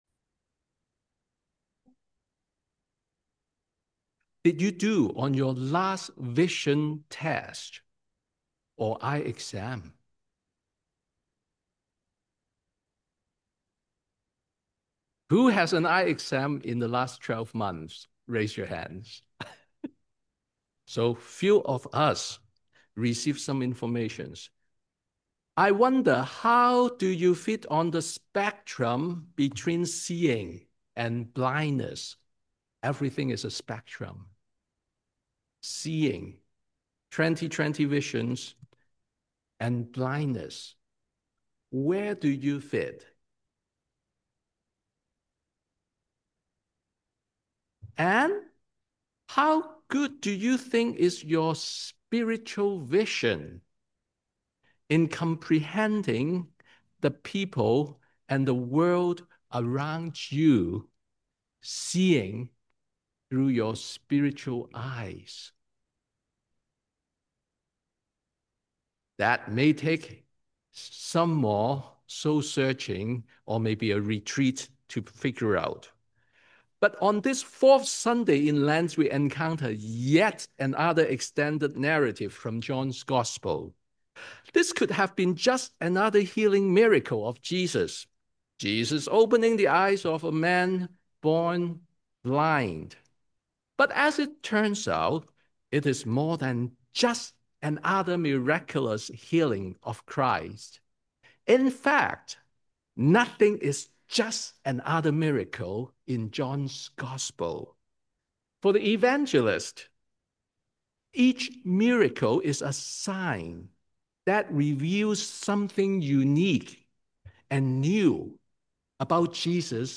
Sermon on the Fourth Sunday in Lent